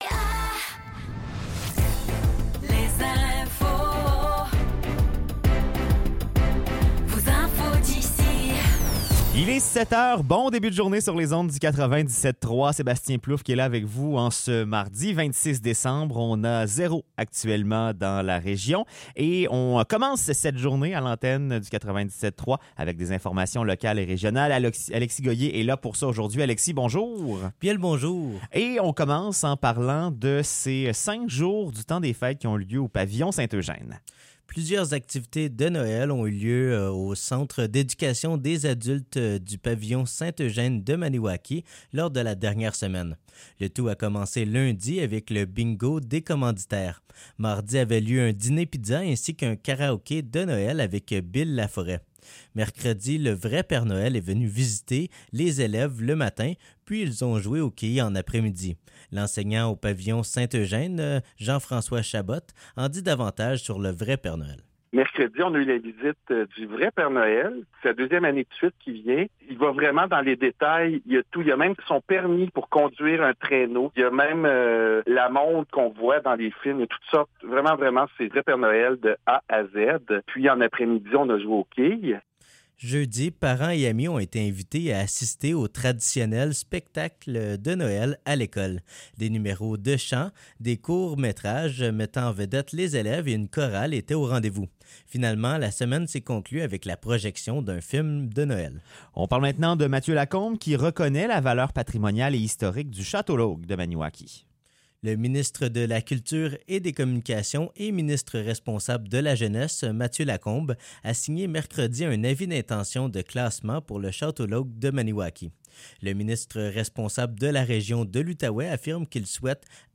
Nouvelles locales - 26 décembre 2023 - 7 h